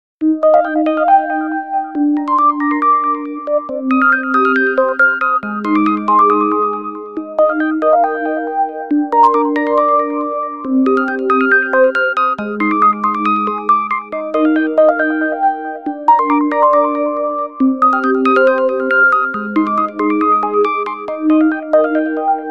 Kategorien Alarm